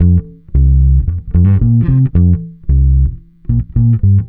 Track 15 - Bass 03.wav